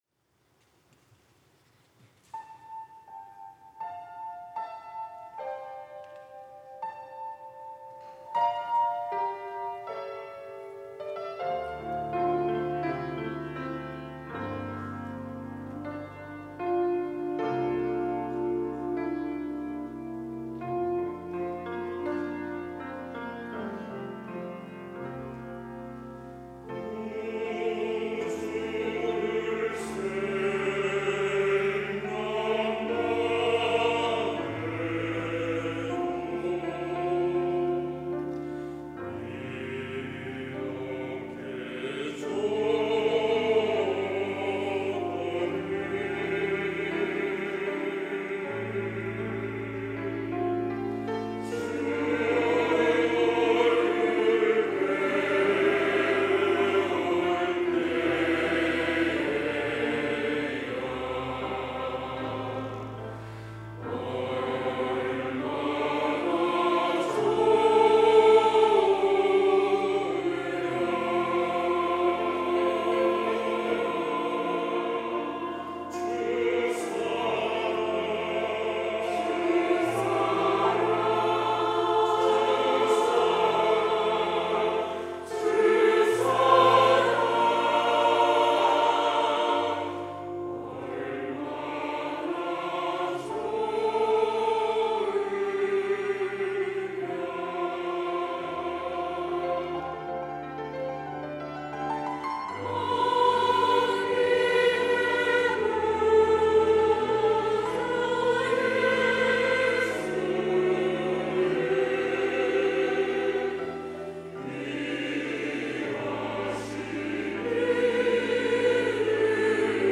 호산나(주일3부) - 구주를 생각만 해도
찬양대